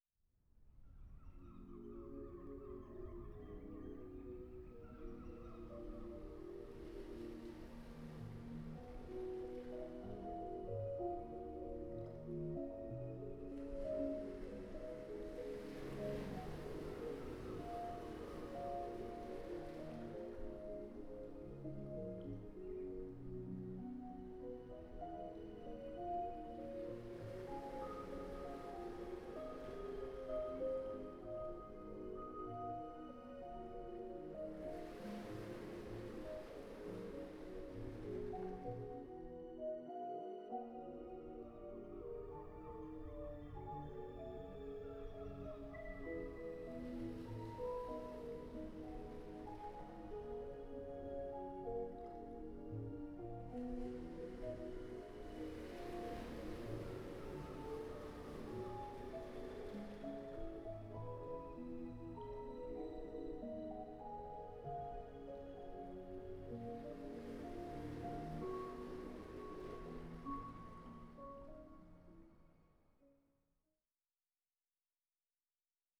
SOUND DESIGN
Makers Space.